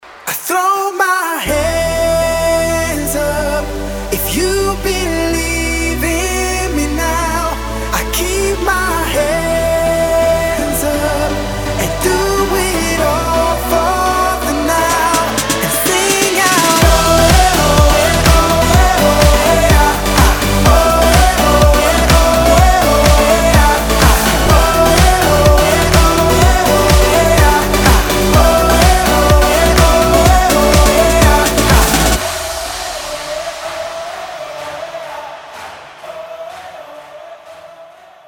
Танцевальные
Быстрая динамичная песня
Метки: club, громкие, dance, мужской вокал, зажигательные,